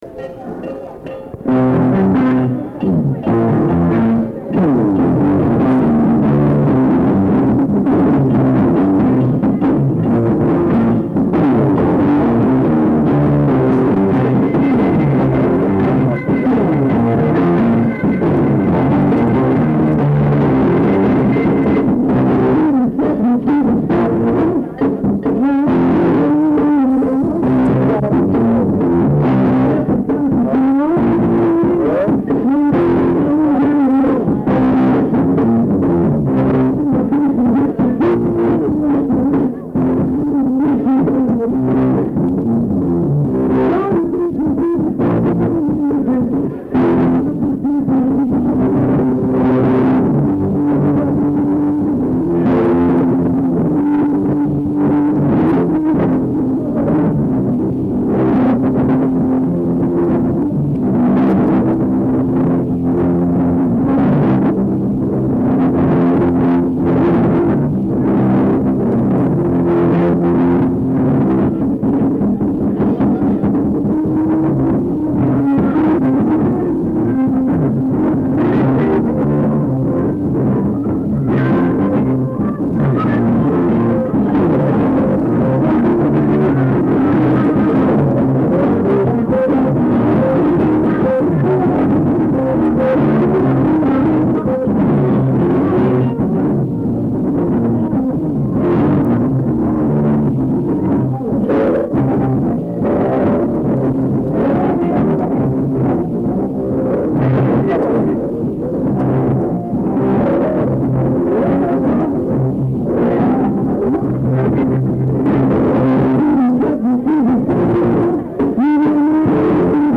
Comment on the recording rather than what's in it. This was an outdoor concert held on a Sunday afternoon in the summer of 1972 in Harrison Smith Park in Upper Sandusky, Ohio. Disclaimer: The audio in the clips is of extremely poor quality! Plus my friends and I were talking throughout.